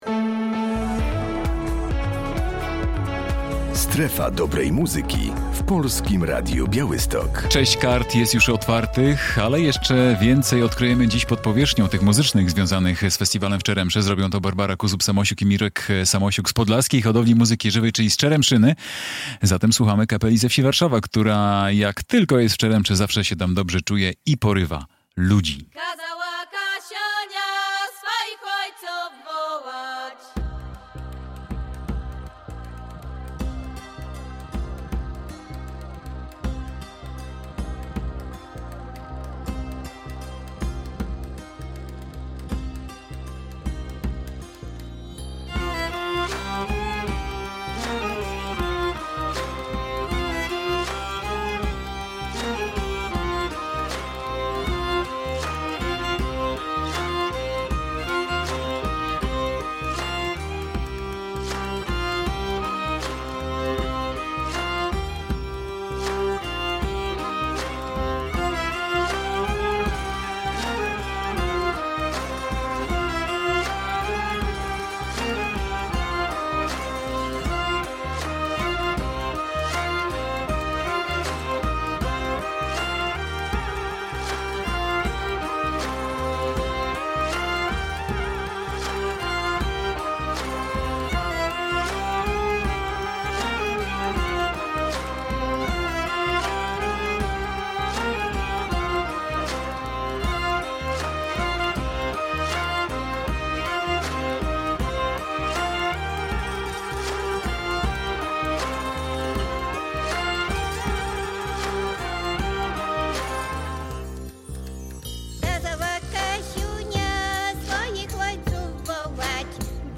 Festiwal folkowy w Czeremsze 2024 !pod powierzchnią!